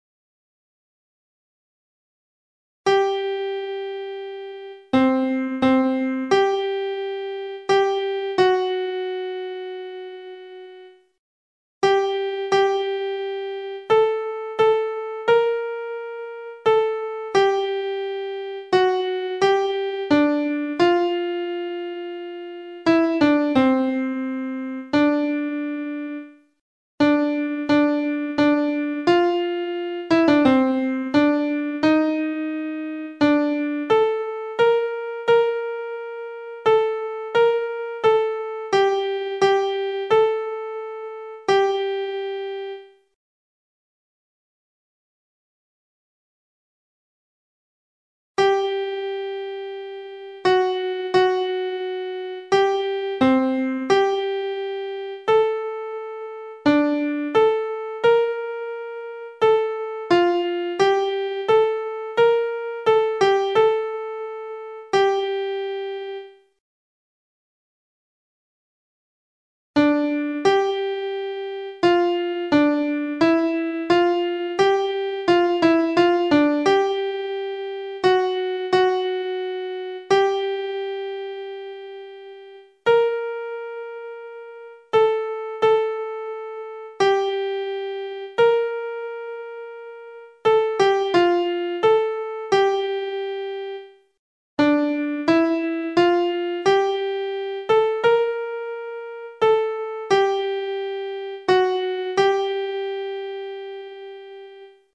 MIDI Contraltos